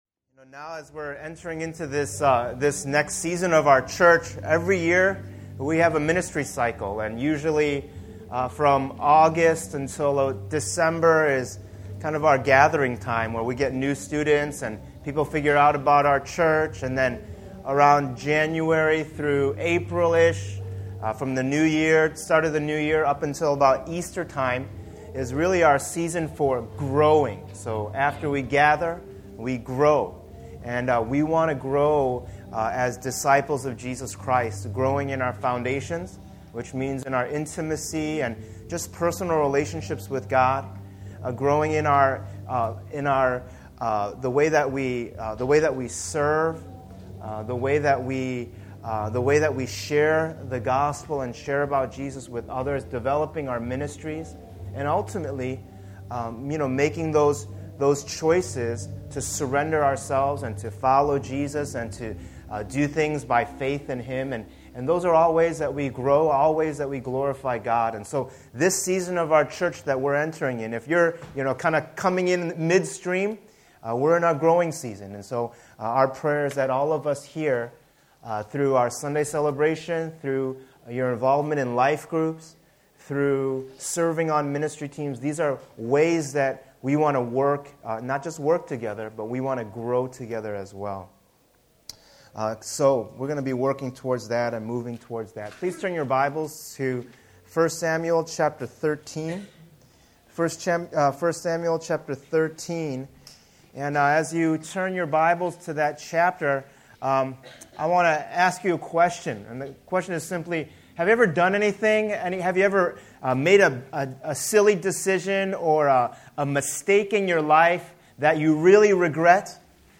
Download Audio Subscribe to Podcast Audio The Kingdom Series This sermon series called “The Kingdom” will go through the books of 1 and 2 Samuel.